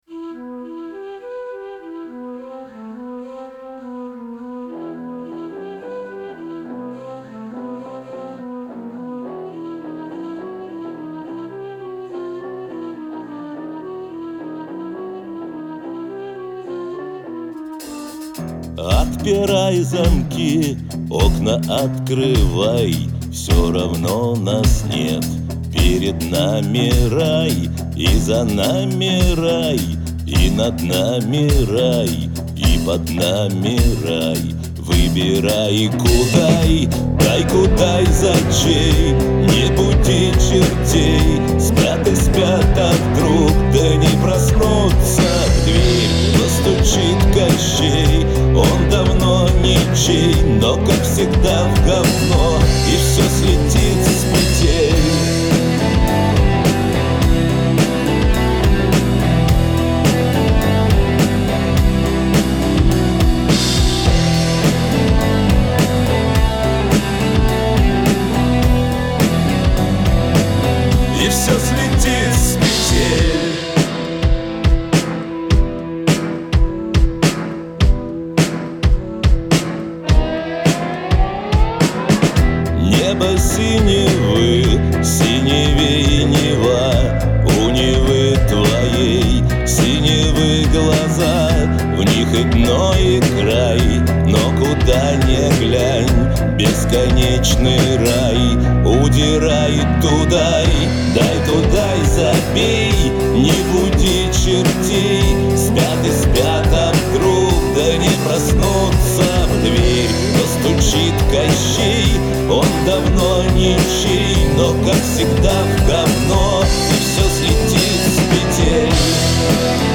Жанр: Rock, Pop